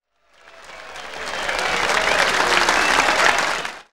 CROWD.wav